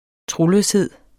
Udtale [ ˈtʁoløsˌheðˀ ]